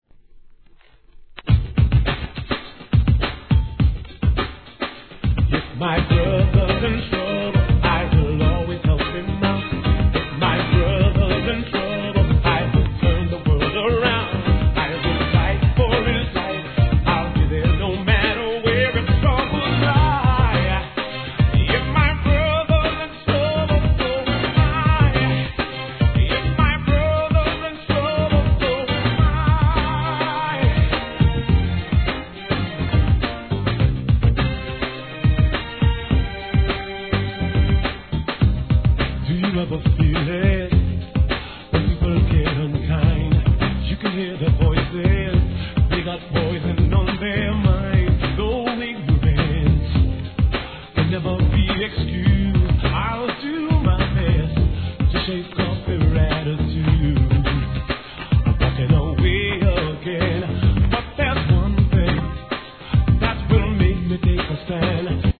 HIP HOP/R&B
Club Mix
Extended Hip Hop Mix
Dub Mix